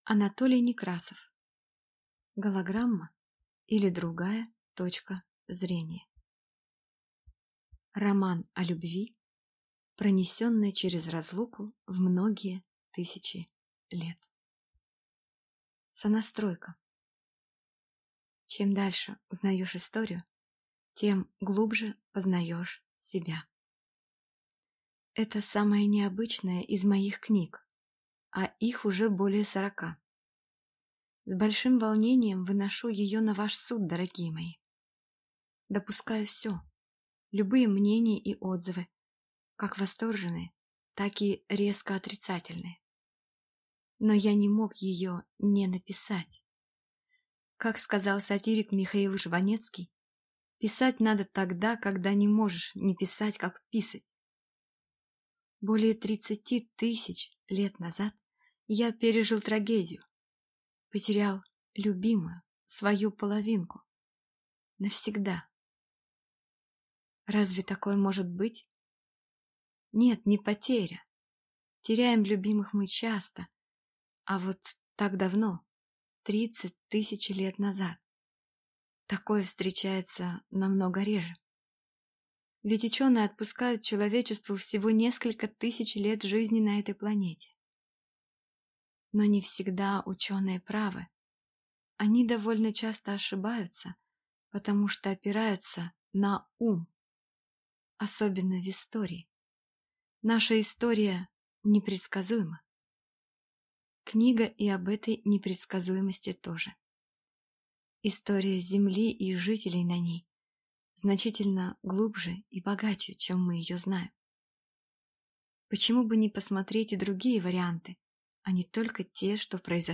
Аудиокнига Голограмма, или Другая точка зрения | Библиотека аудиокниг